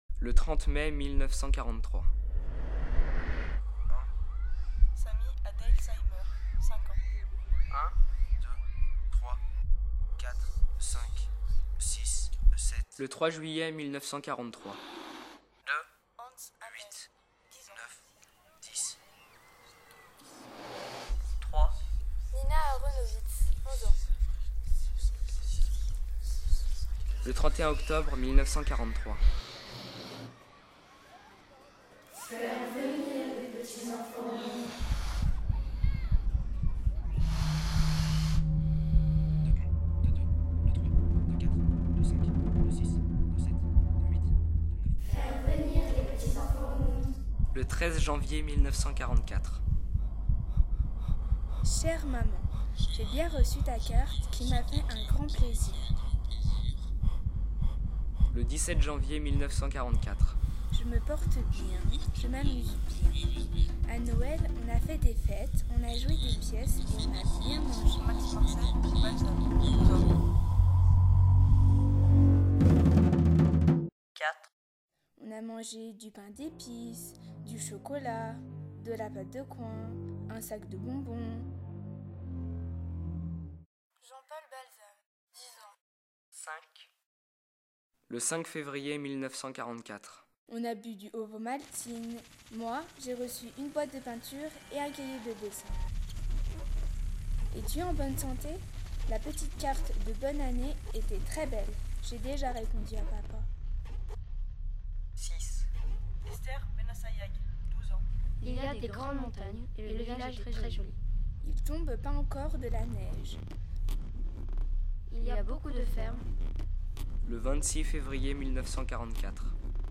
Interprétée par la classe de 3ème rose Collège Lamartine de Crémieu
Interprétée par la classe de 3ème rose